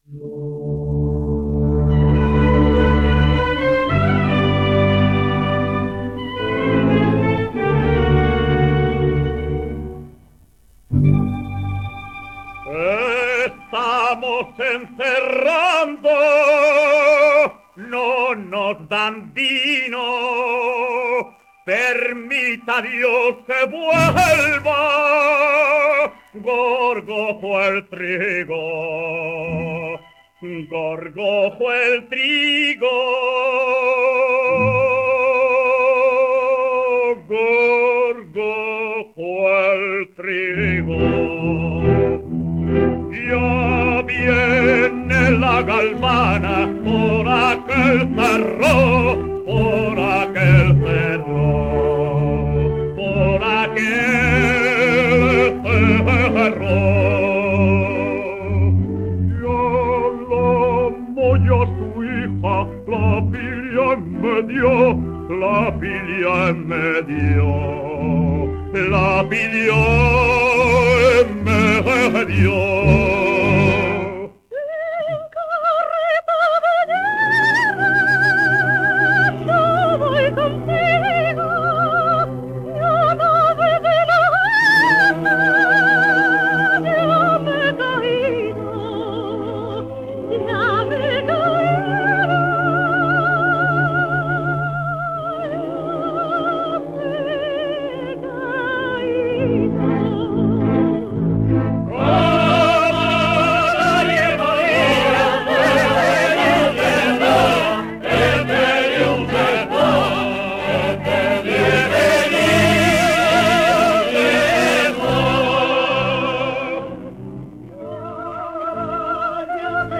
Zarzuela en tres actos
78 rpm